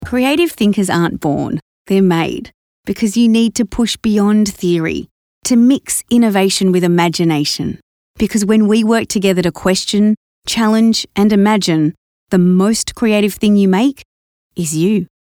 Female
English (Australian)
Adult (30-50)
A friendly, warm and empathic voice with a bubbly and catchy energy.
E-Learning
Authorotative, Teaching